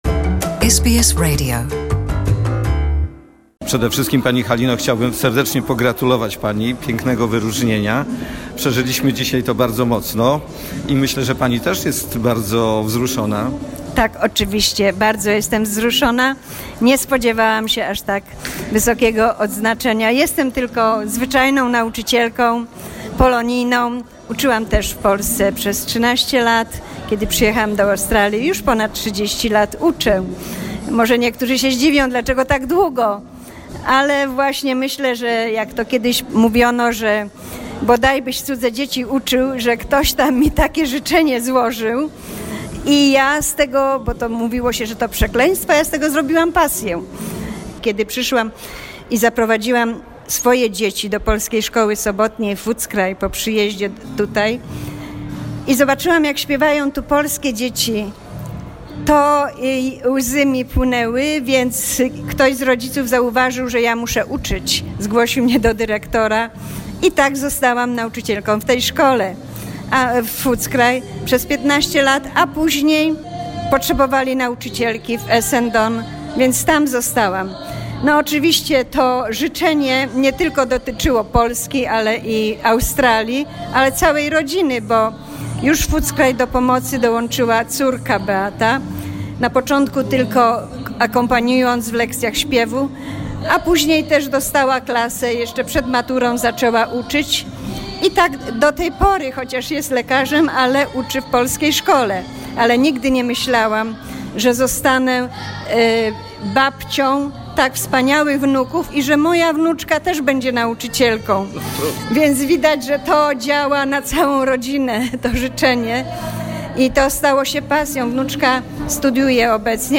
Charity President's Ball in 'Syrena' Source: SBS